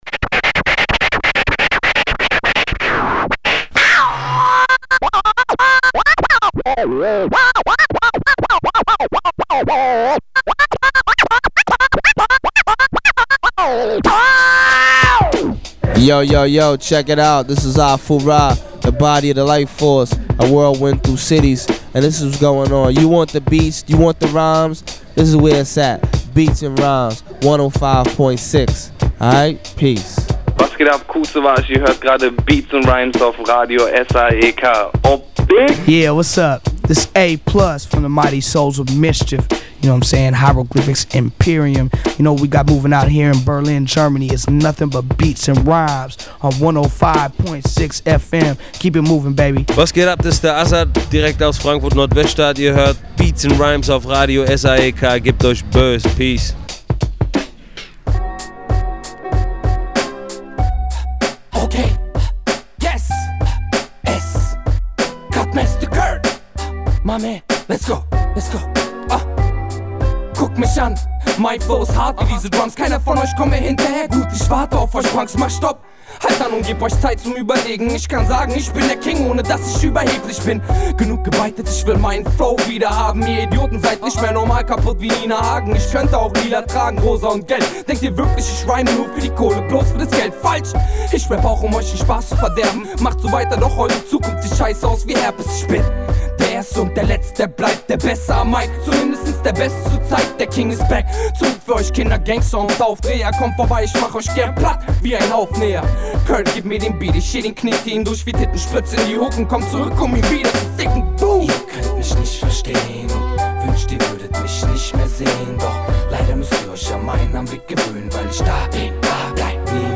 Inhalte: Newz, VA Tips, Musik